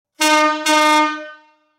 • Som agudo e de alta frequência;
Buzinas Automotivas Marítima